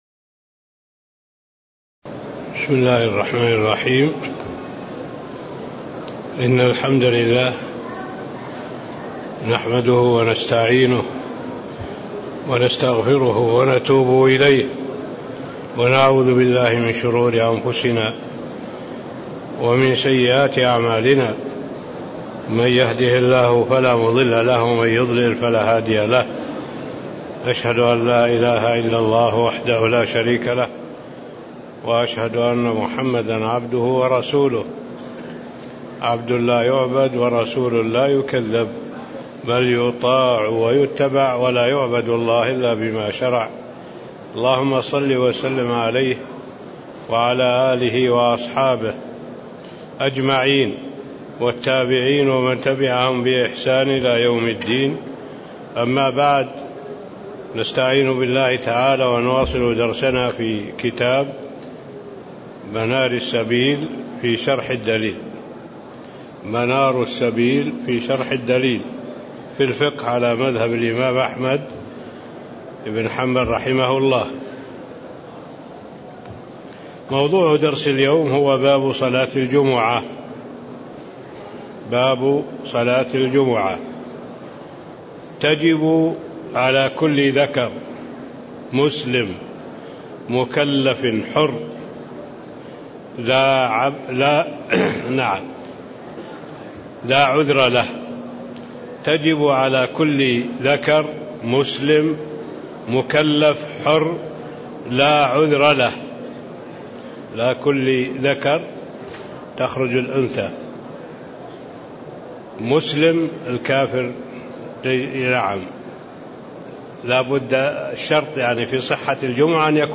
تاريخ النشر ٢٠ رجب ١٤٣٦ هـ المكان: المسجد النبوي الشيخ